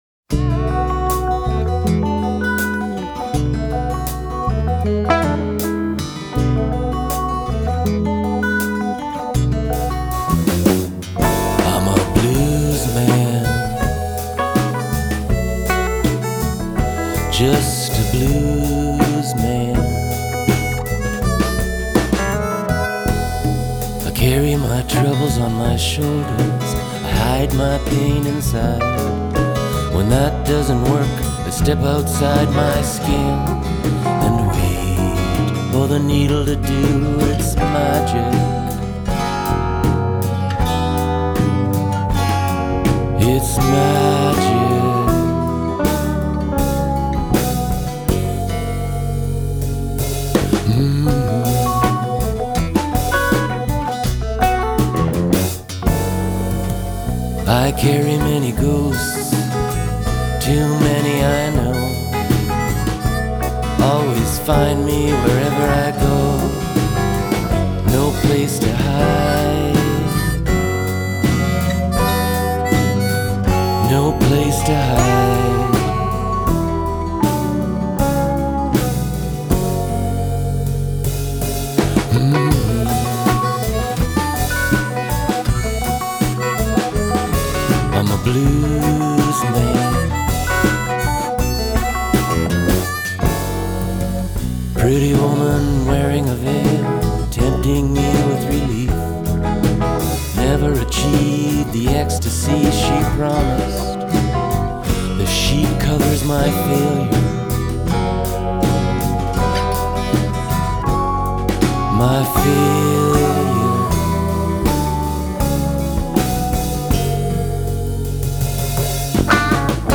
Recorded in Winnipeg in September 2021
acoustic guitar/keyboard/vocals
harmonica/vocals
electric guitar
bass
drums
hand drum